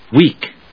/wíːk(米国英語), wi:k(英国英語)/